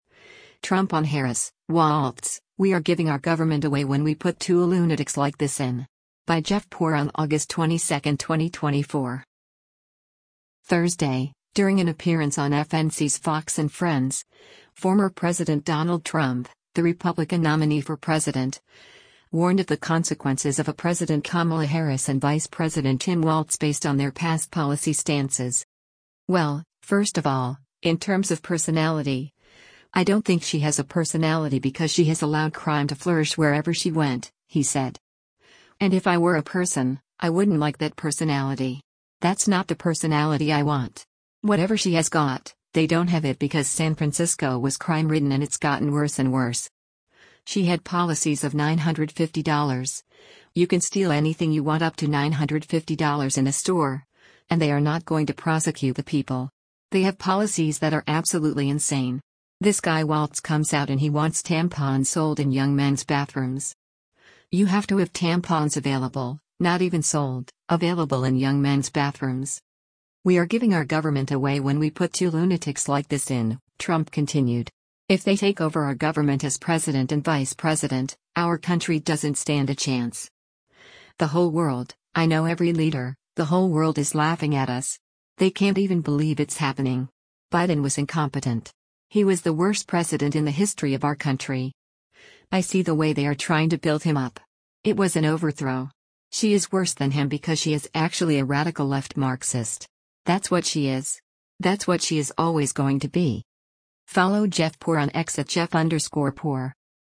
Thursday, during an appearance on FNC’s “Fox & Friends,” former President Donald Trump, the Republican nominee for president, warned of the consequences of a “President” Kamala Harris and “Vice President” Tim Walz based on their past policy stances.